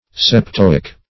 Septoic \Sep*to"ic\, a.